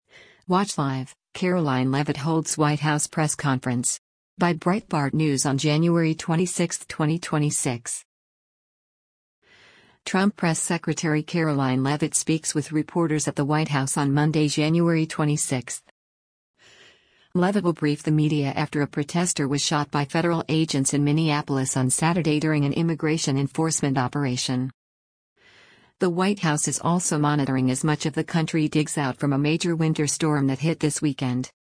Trump Press Secretary Karoline Leavitt speaks with reporters at the White House on Monday, January 26.